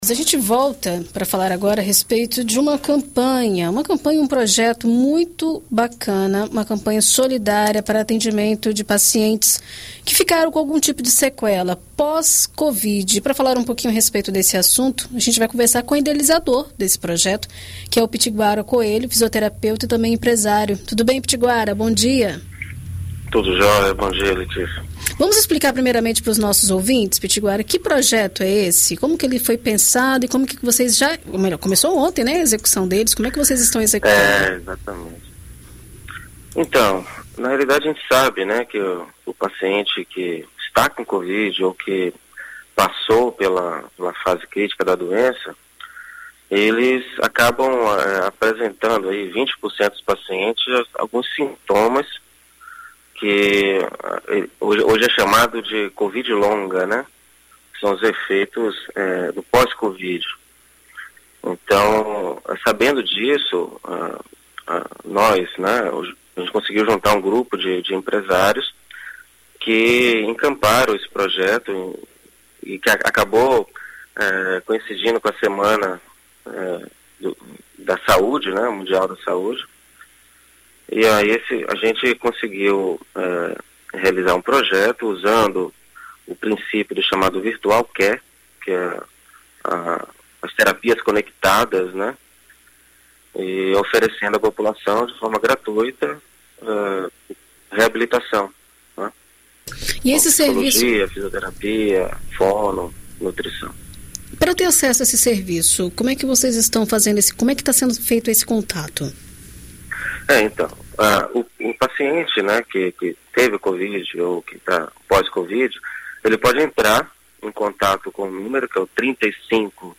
Na BandNews FM